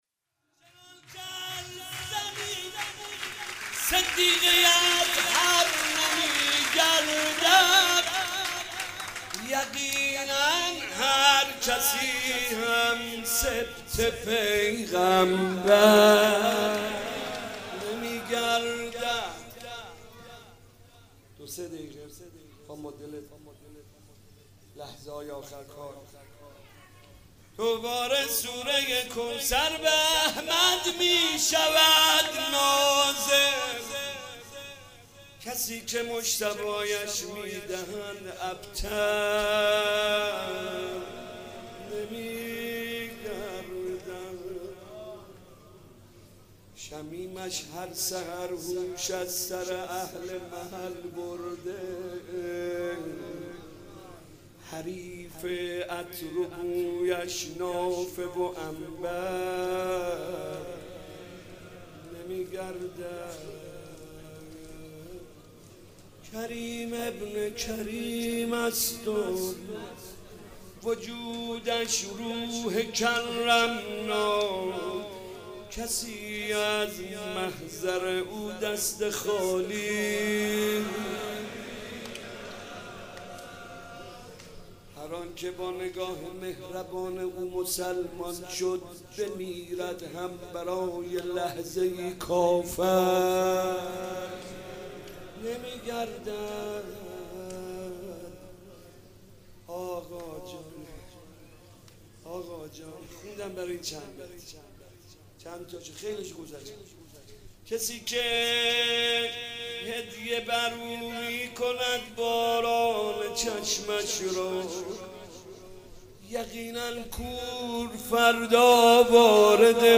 مدح و روضه
مناسبت : شب شانزدهم رمضان
مداح : محمدرضا طاهری قالب : مدح روضه